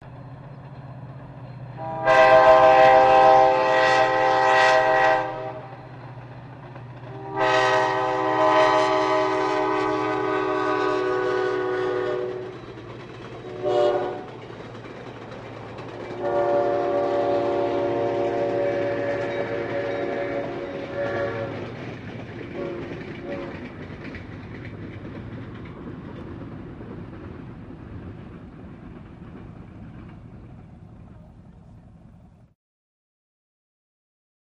Train By, In and Long Away, Doppler Whistle